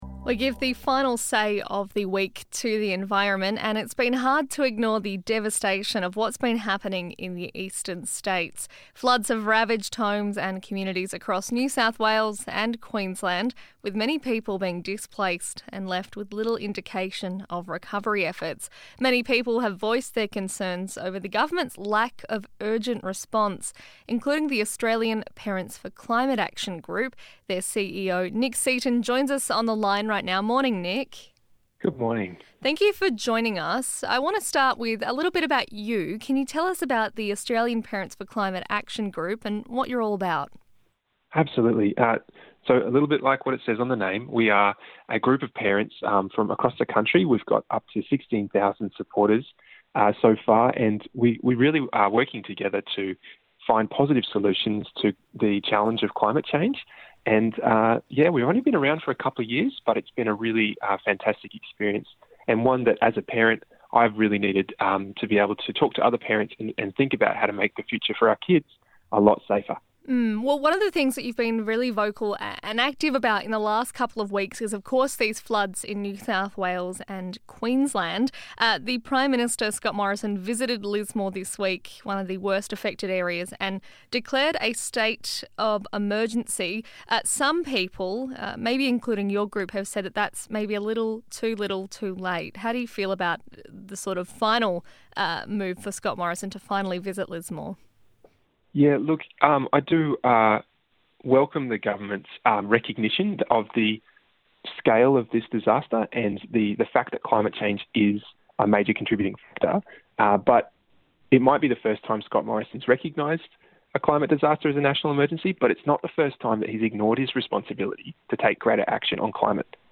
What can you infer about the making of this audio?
live on Breakfast to discuss the situation and how people can get involved in recovery efforts.